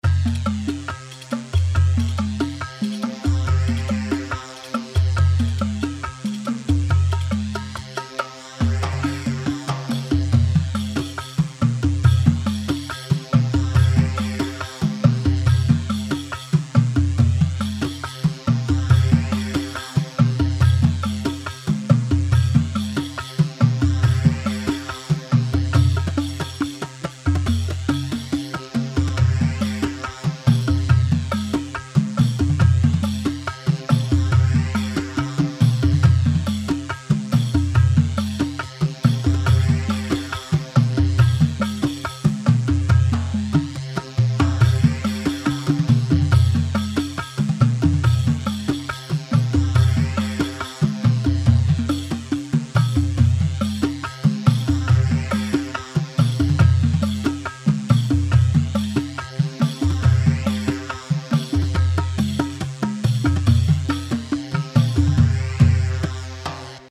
Rhuma 4/4 140 رومبا
Rhumba-Live-140.mp3